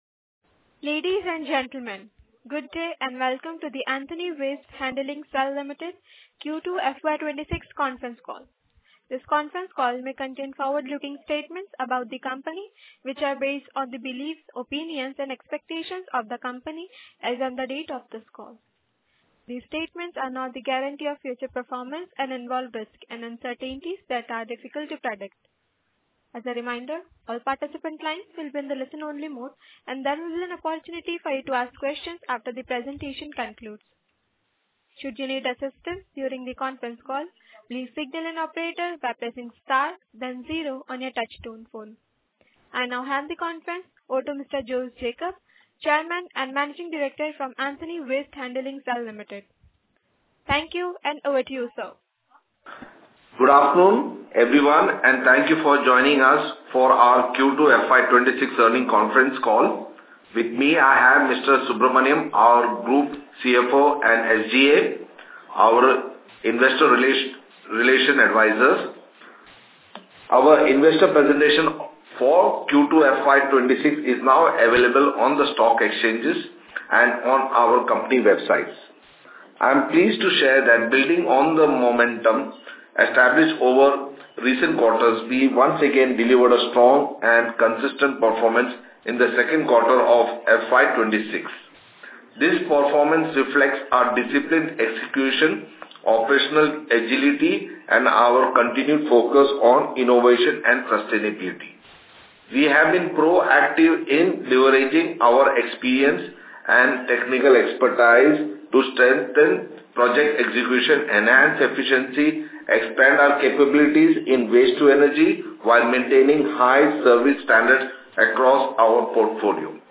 Earnings Call Audio Recording
EarningscallQ2FY26.mp3